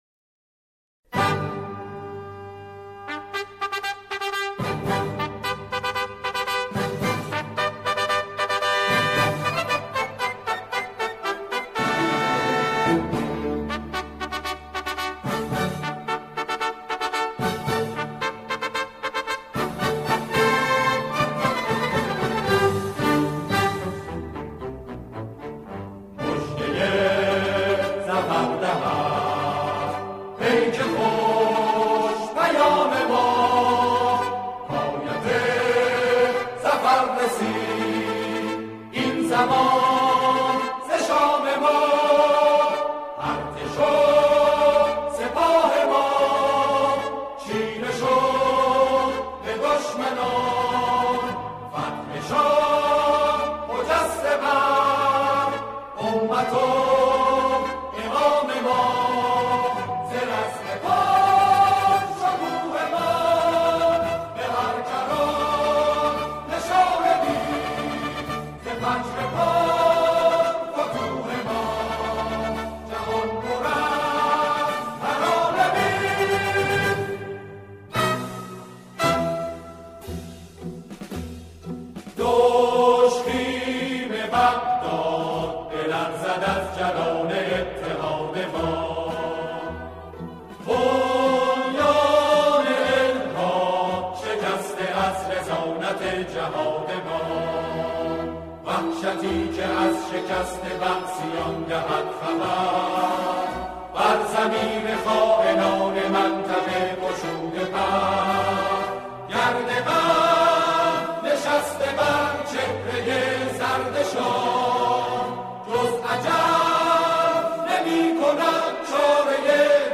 سرود نوستالژی